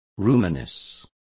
Complete with pronunciation of the translation of roominess.